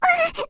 combobreak.wav